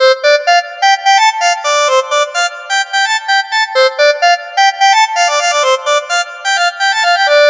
描述：新订单声音
标签： 娱乐 人声 外卖
声道立体声